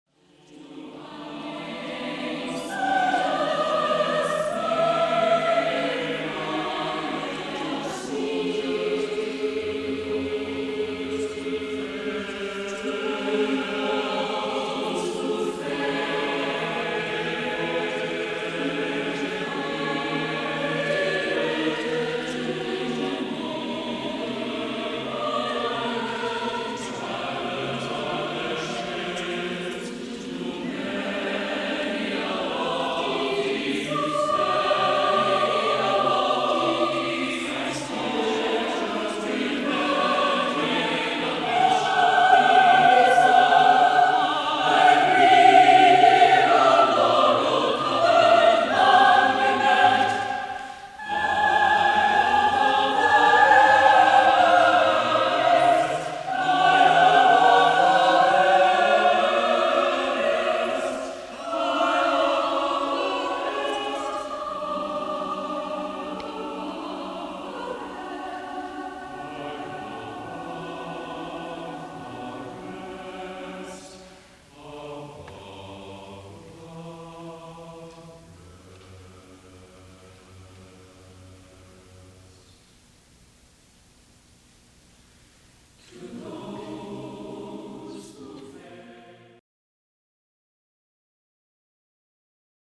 Vocal/Choral
for SATB chorus unaccompanied